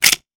weapon_foley_pickup_24.wav